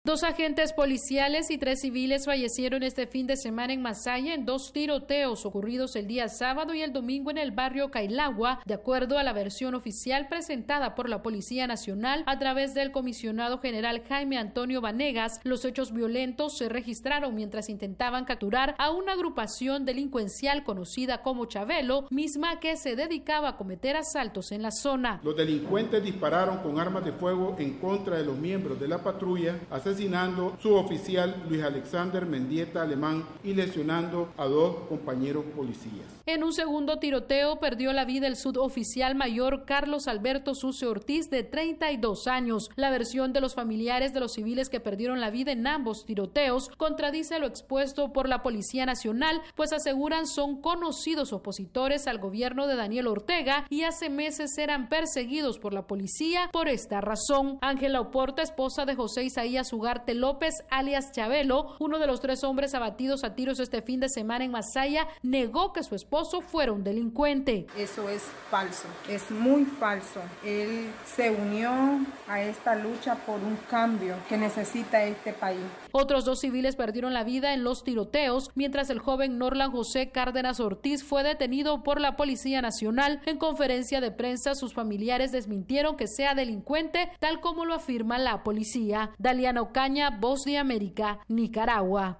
VOA: Informe de Nicaragua